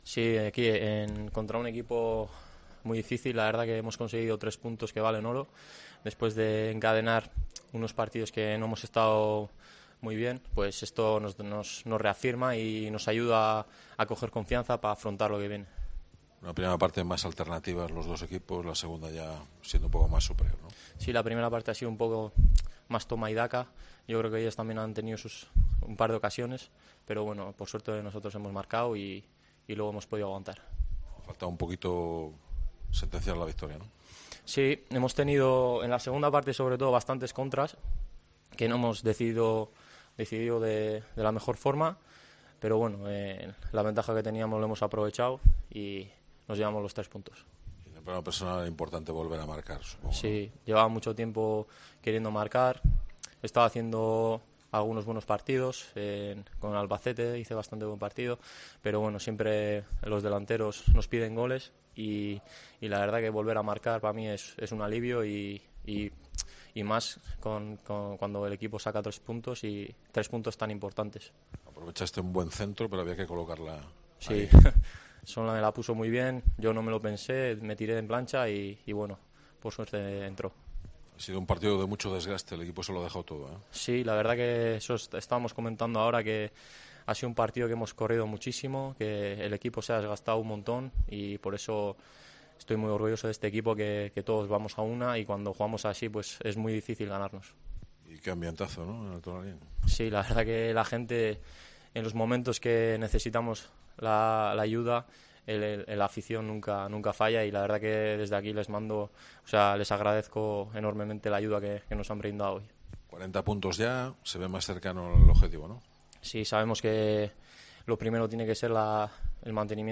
POSTPARTIDO